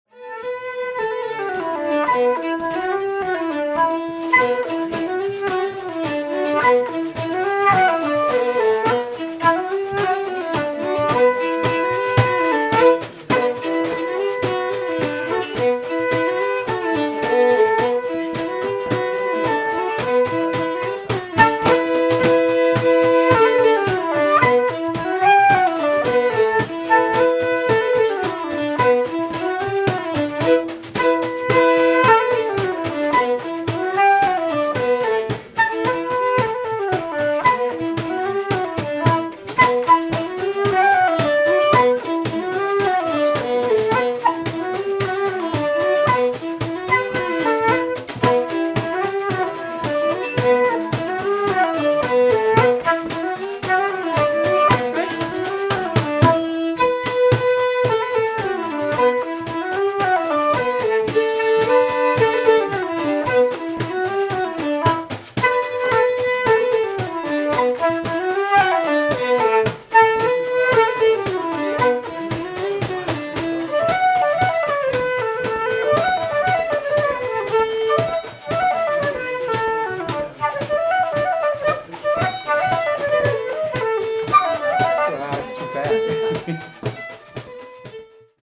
A new sound coming out of the door from Aristo’s bar this evening – the sound of the flute and fiddle from two musicians just arrived in the city. What the regulars to the bar were treated to was some authentic Celtic traditional music, coming not from Scotland or Ireland or even Brittany but instead from the other side of the ocean from Fairbanks in Alaska.
flute
fiddle
Irish Drum